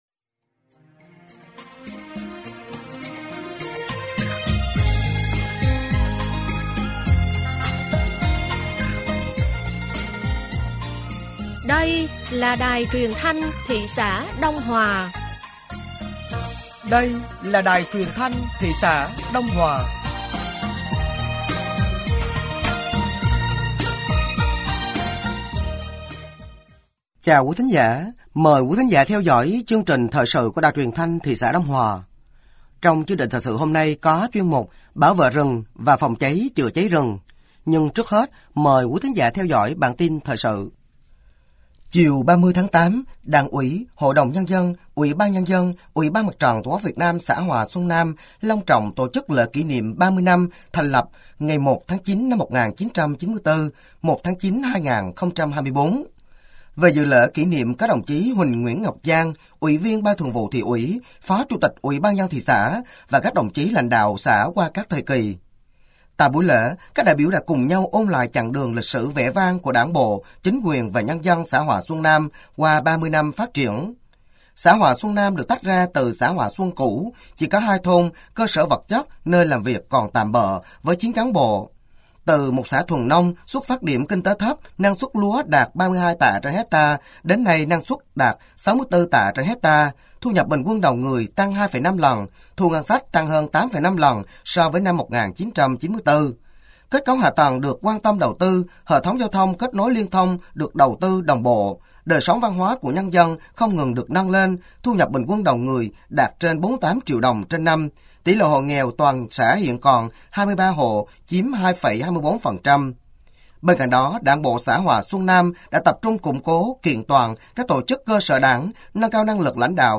Thời sự tối ngày 31 tháng 8 và sáng ngày 01 tháng 9 năm 2024